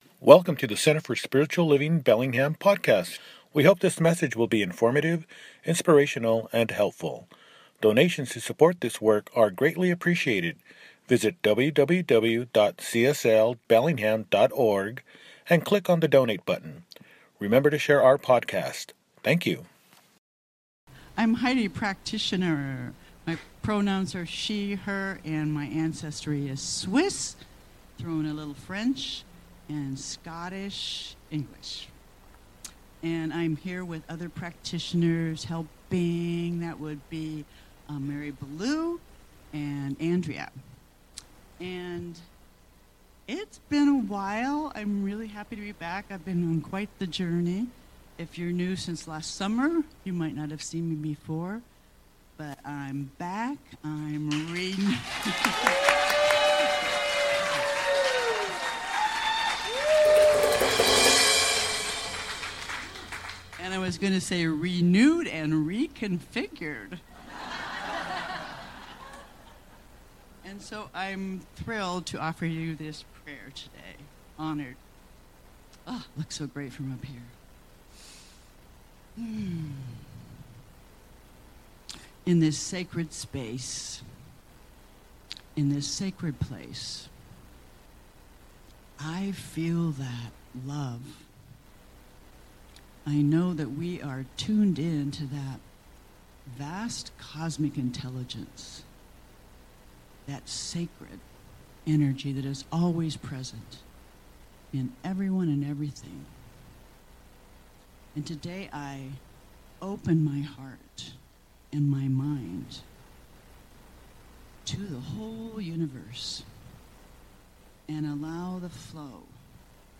Jan 11, 2026 | Podcasts, Services